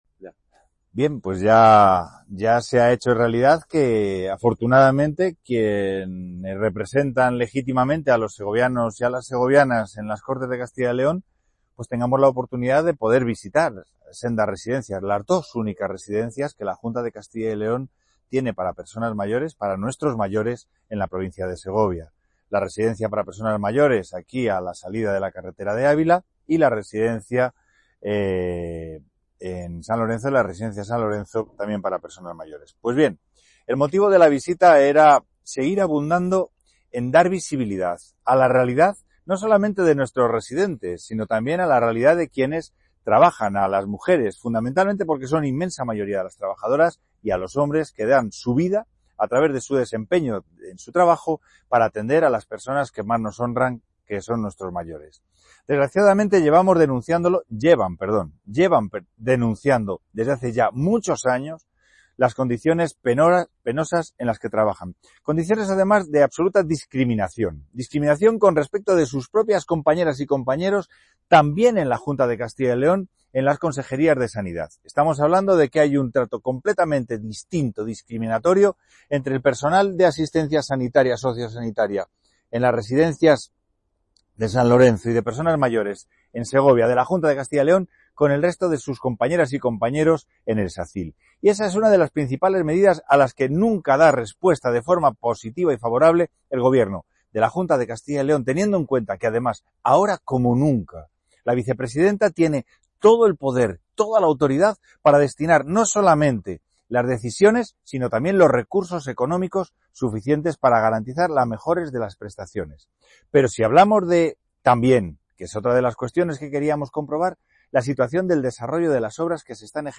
Antes de la visita, ofreció una rueda de prensa, junto al comité de Empresa de las residencias de personas mayores, en la que denunció la situación de precariedad que sufren ambas instalaciones y señalaron la falta de compromiso de la Junta con los mayores de la provincia.